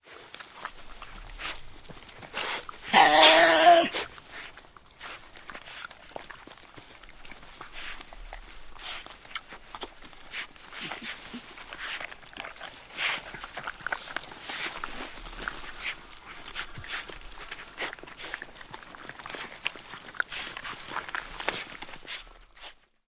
Тасманийский дьяволенок поедает тушу животного